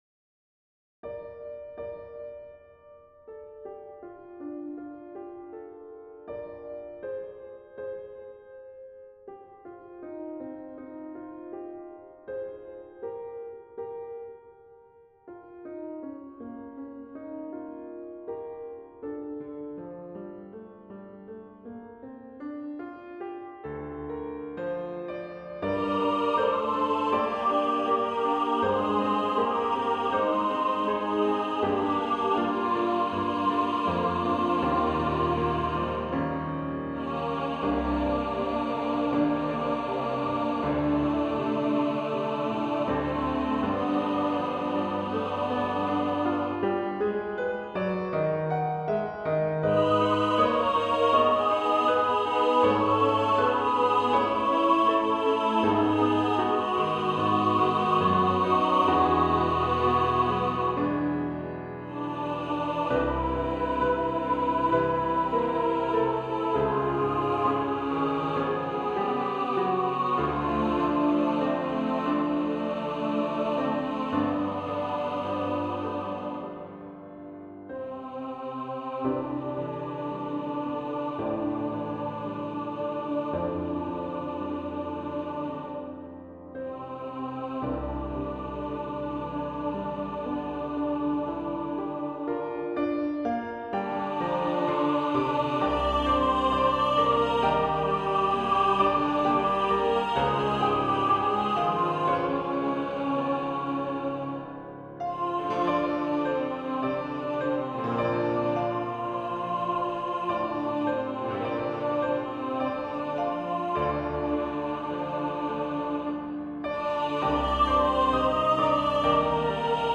SAB and piano